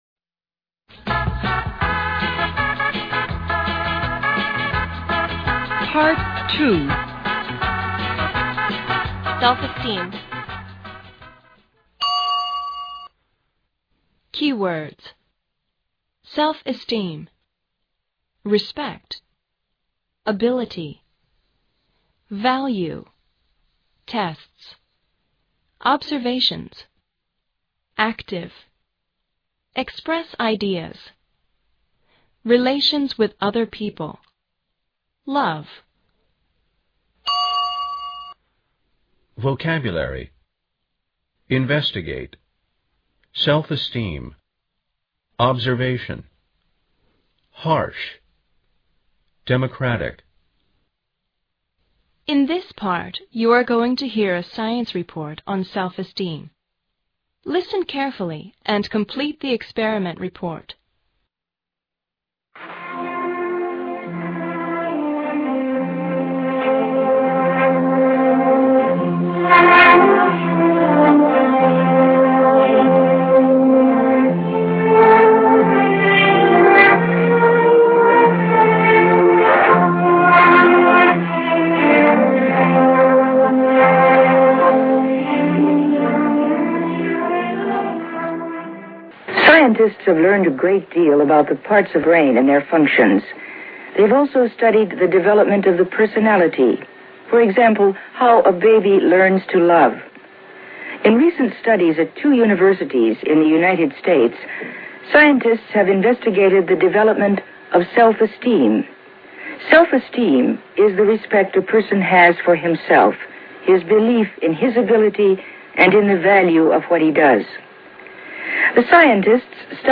In this part, you're going to hear a science report on self-esteem.